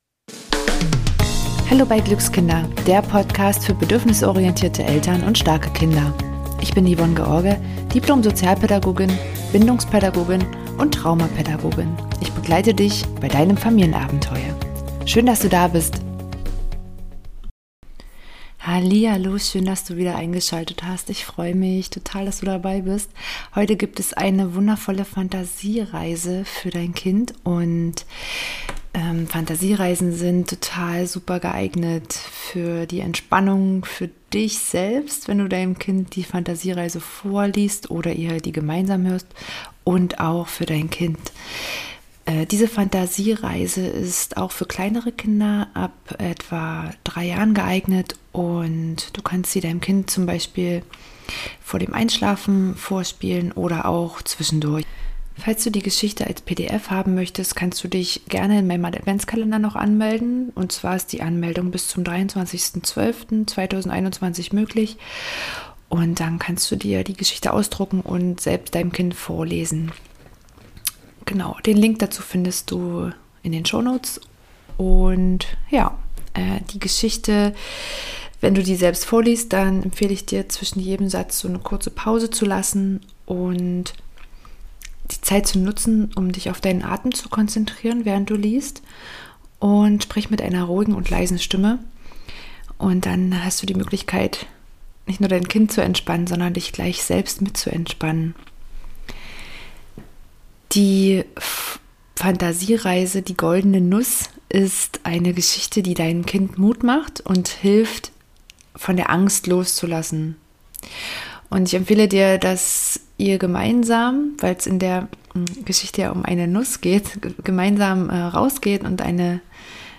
#6 Fantasiereise für Kinder - Tschüss Angst, hallo Mut! ~ Glückskinder Podcast